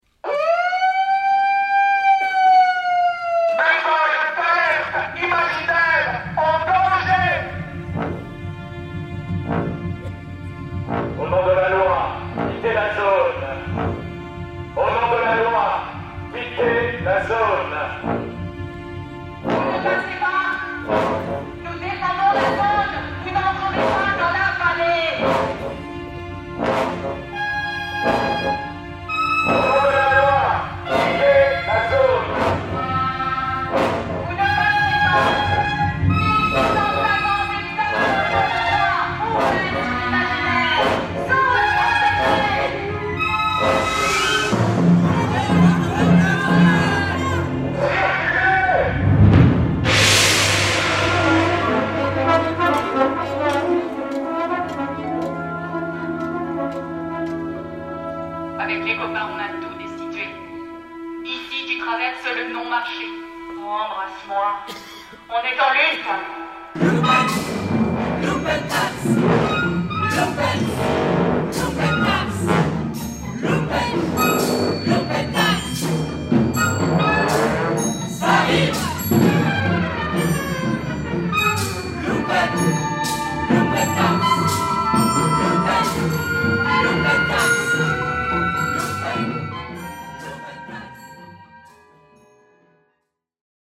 pour 8 jeunes comédiens et 48 jeunes musiciens